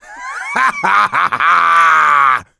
Left 4 Dead 2 Coach Laugh-sound-HIingtone